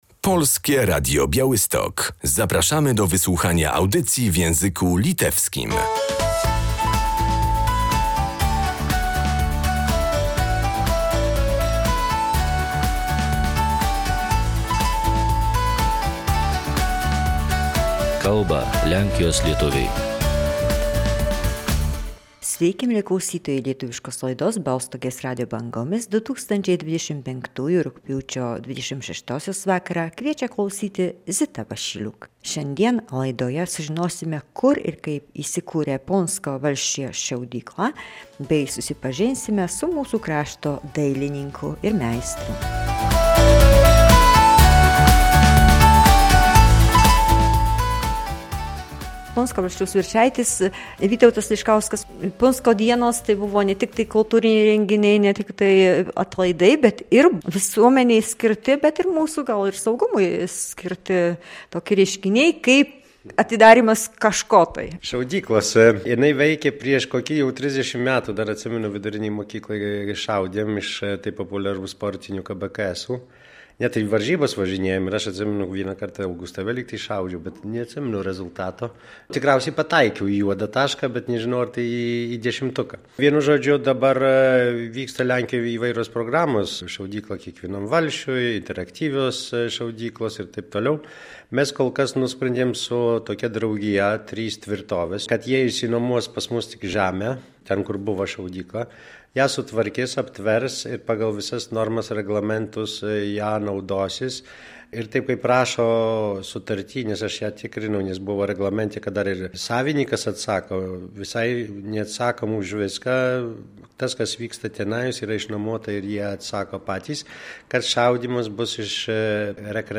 Strzelnica dla mieszkańców, którą dzierżawi zewnętrzna organizacja jest już dostępna. Jakie zasady obowiązują i z jakich rodzajów broni można strzelać – opowiada wójt gminy Puńsk Witold Liszkowski.